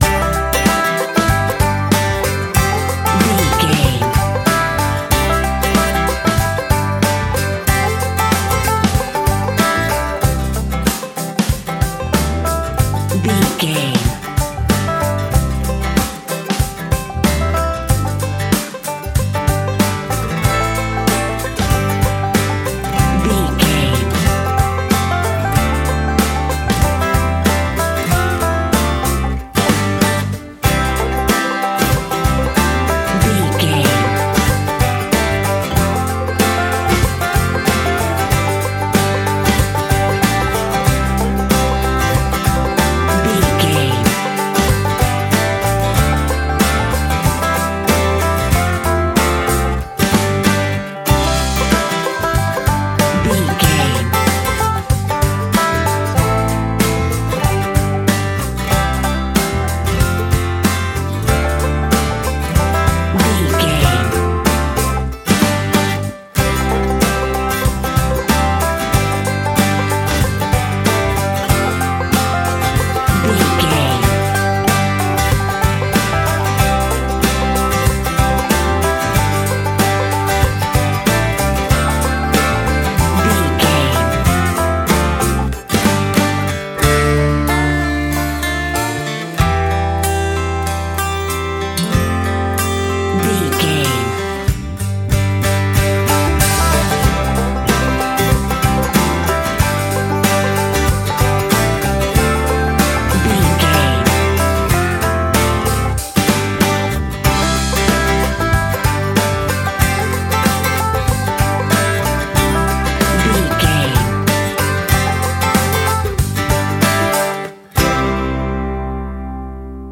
Ionian/Major
acoustic guitar
banjo
bass guitar
drums
Pop Country
country rock
bluegrass
uplifting
driving
high energy